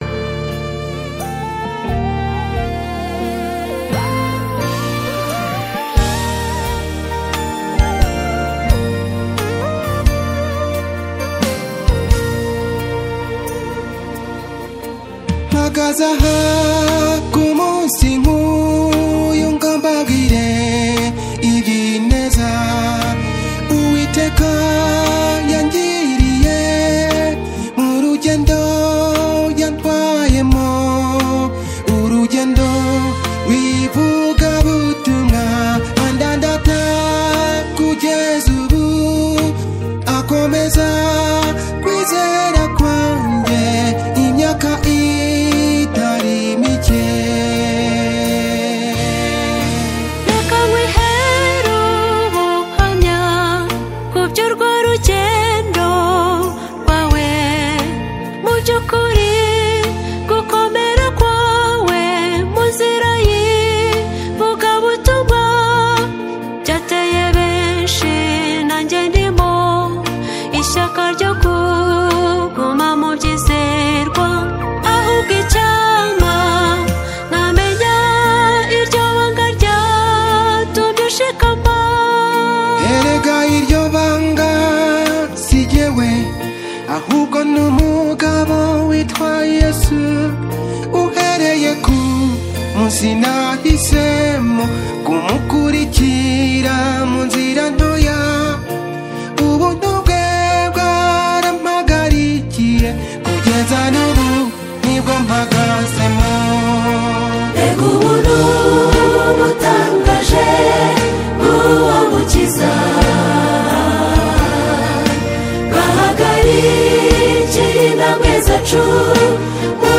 heartfelt song of thanksgiving